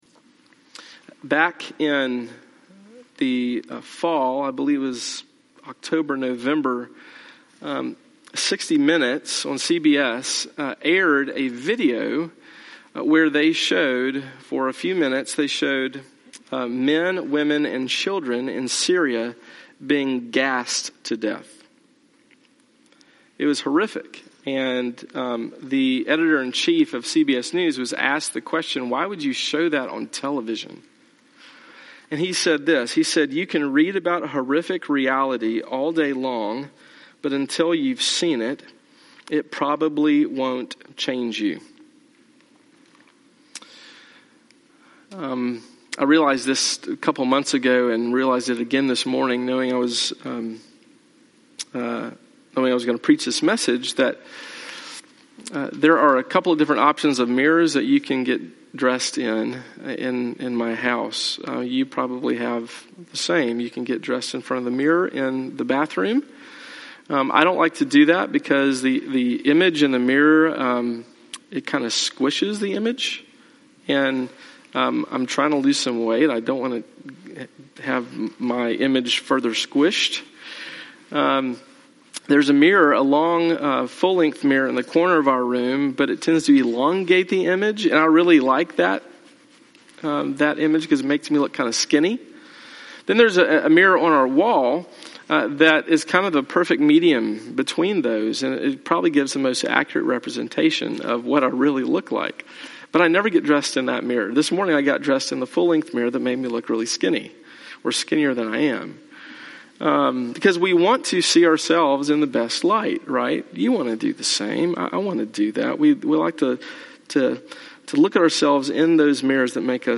(Guest preacher)